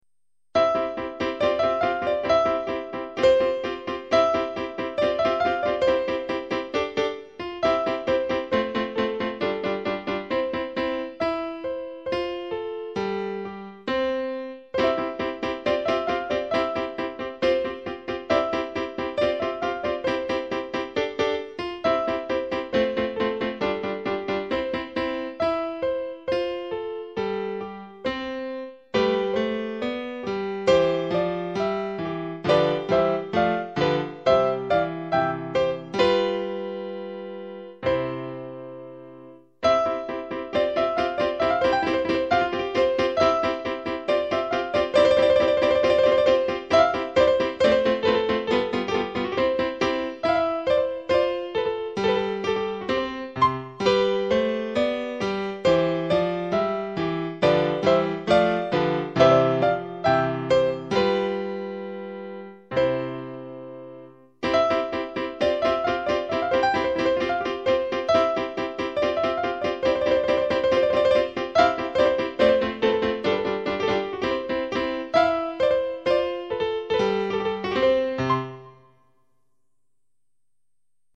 Klassik
Das Zweite ist auch sehr schön, das gefällt mir sehr vor allem das Thema im Klavier und die Begleitung darunter.